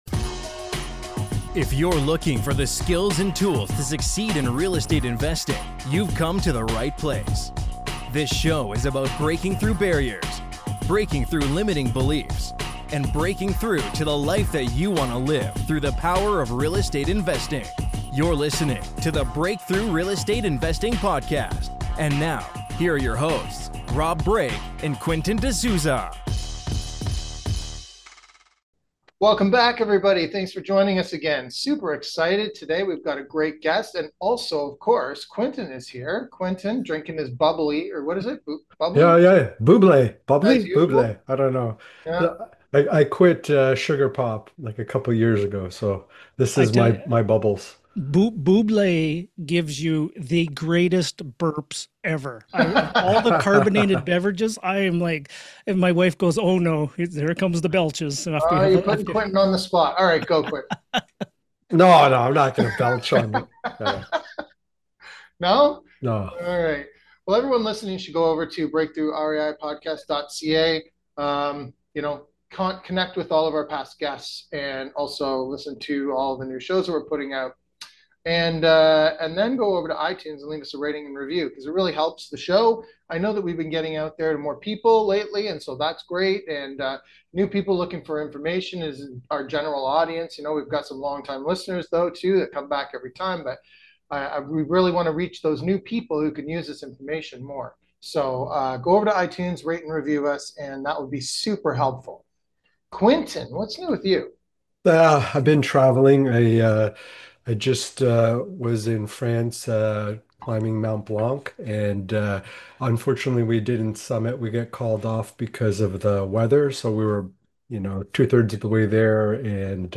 Here's What You'll Learn in our Interview